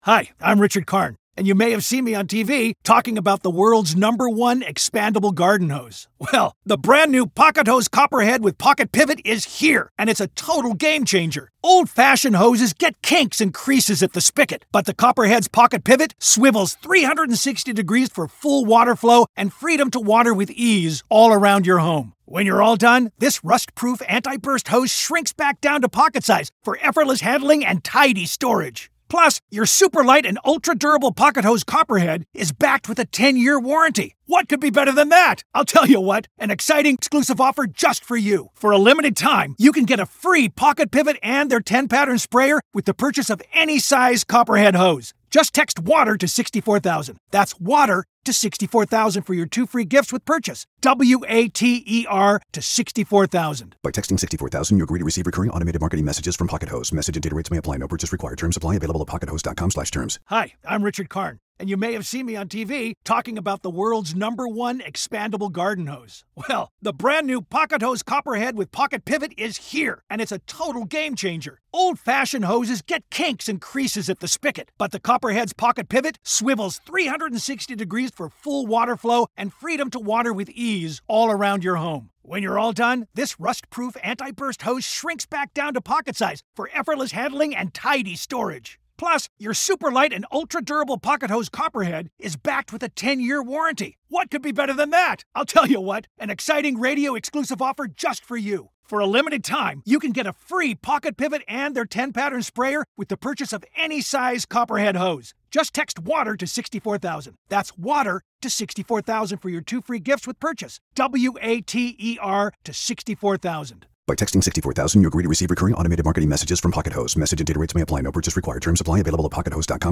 The conversation touched on the polarizing nature of Baldwin's public persona and how it could influence jurors' perceptions and decisions.